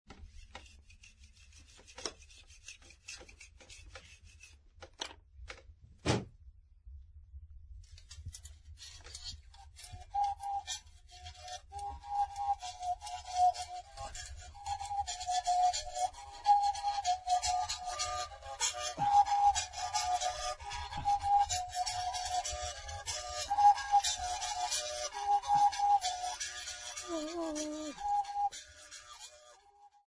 Folk music
Sacred music
Field recordings
Africa South Africa Ngqoko sa
Ngqoko music ensemble accompanied by umrhubhe
7.5 inch reel